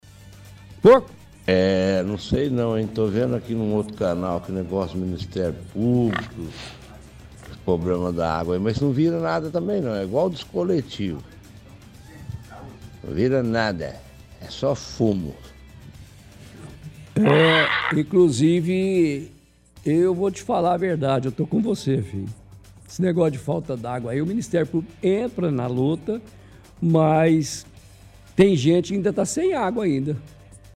– Ouvinte diz que mesmo com Ministério Público entrando na situação, não há certeza de que Dmae vai seguir as recomendações.